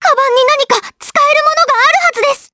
Estelle-Vox_Skill3_jp_b.wav